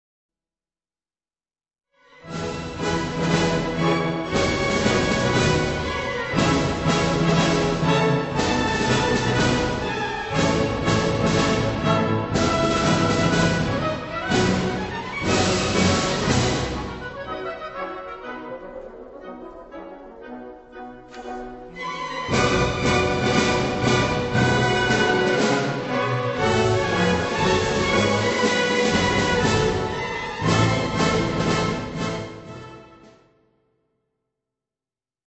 November 2002 Concert
Snappy side drums